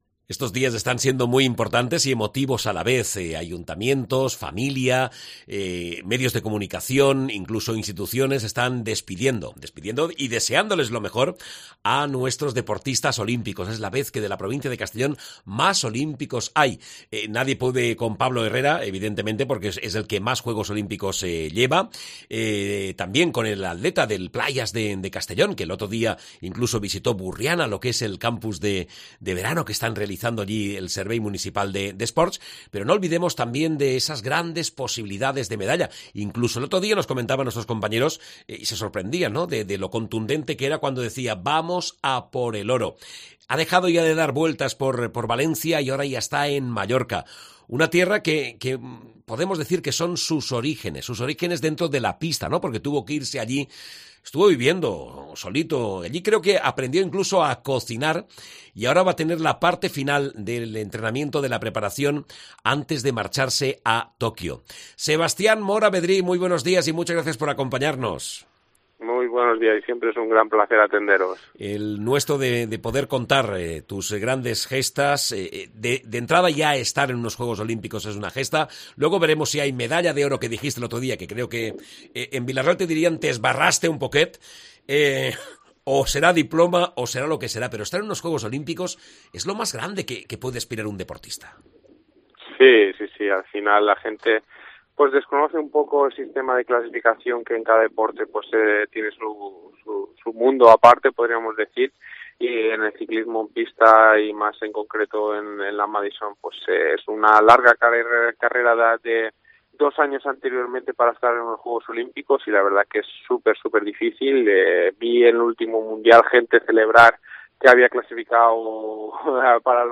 Mora no se conforma con cualquier medalla y en su cabeza únicamente pasa la opción de ser campeón olímpico y regresar con la medalla de oro. de hecho, según ha explicado en una entrevista concedida a la Cadena COPE, Mora señala que " me preguntaron si firmaba una plata o un bronce y tanto Albert como yo dijimos que no ".